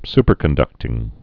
(spər-kən-dŭktĭng)